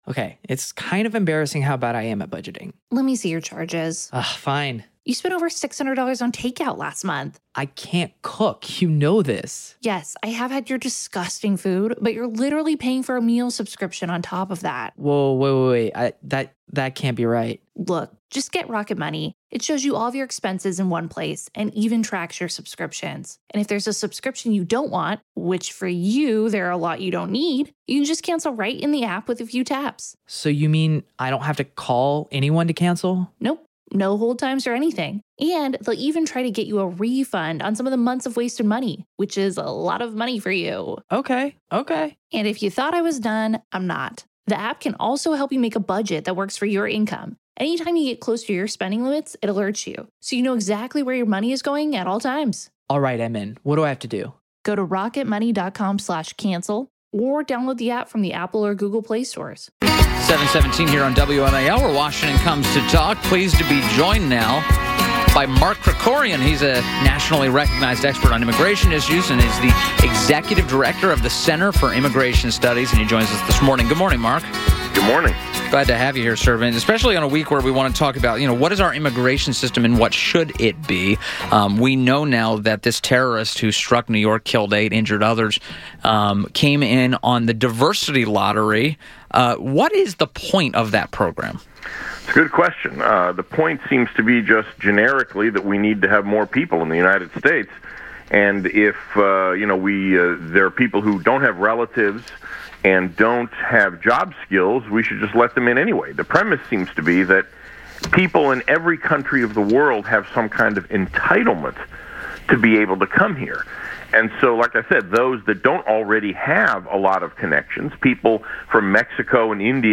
INTERVIEW – MARK KRIKORIAN – a nationally recognized expert on immigration issues, has served as Executive Director of the Center for Immigration Studies (CIS) – discussed diversity visas.